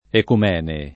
ecumene [ ekum $ ne ]